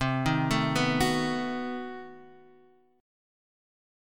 B7/C Chord